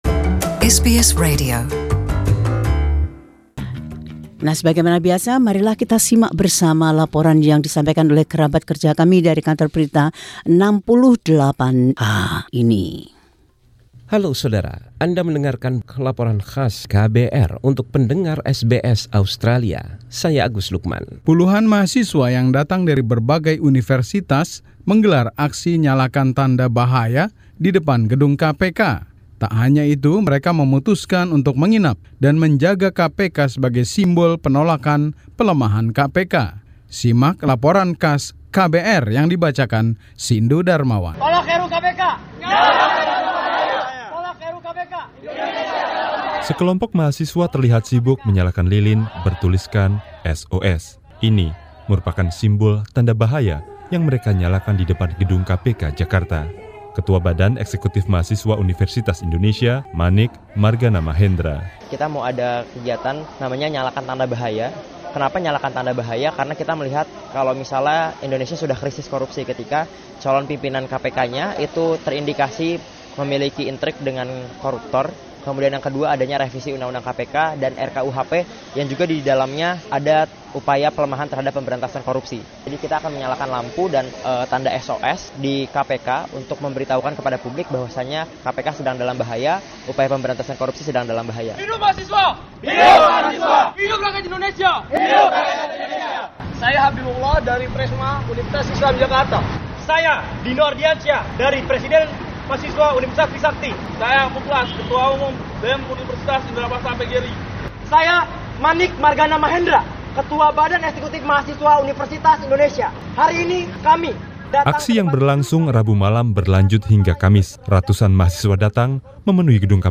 This special report from the team at KBR 68H explains the students’ anger.